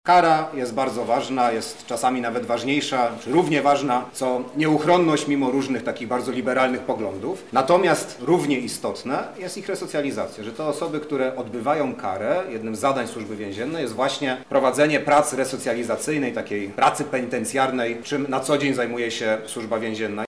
• mówi Michał Woś, wiceminister sprawiedliwości.